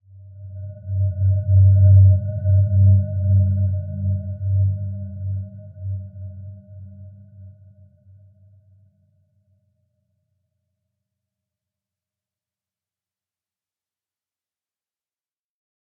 Dreamy-Fifths-G2-f.wav